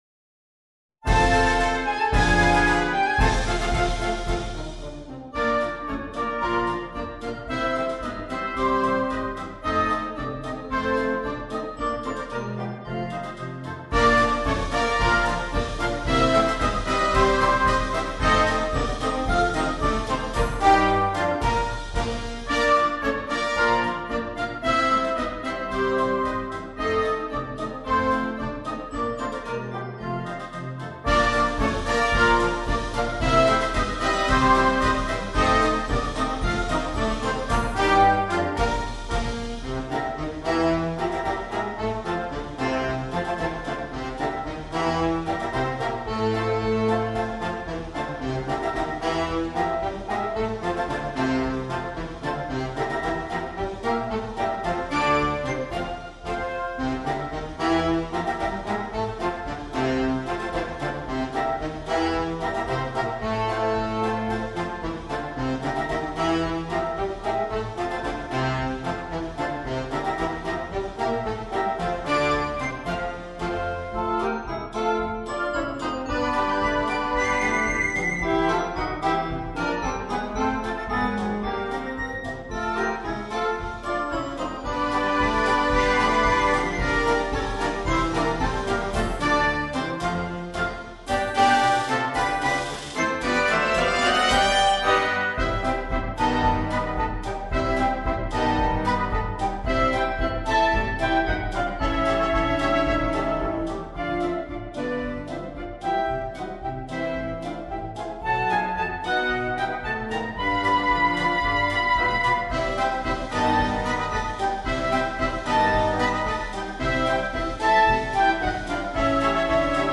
Marcia brillante